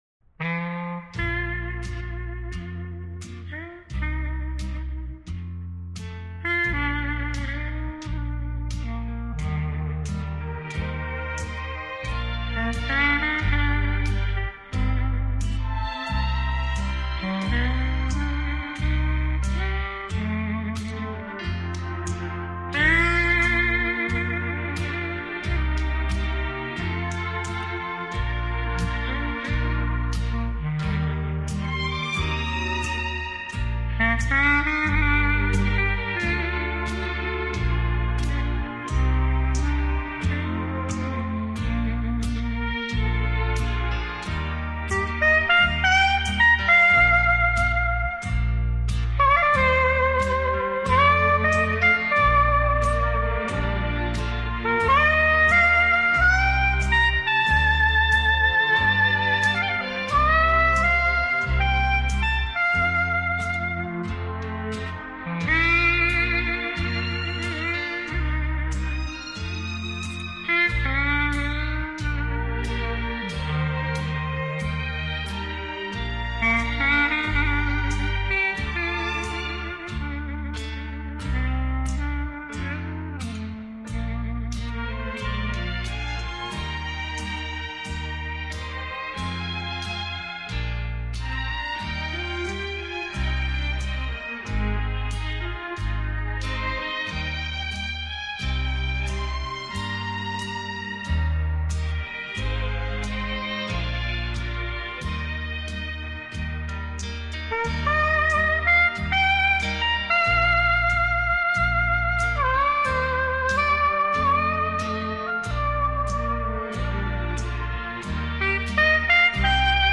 재즈 클라리넷
구슬프게 하소연하는 듯한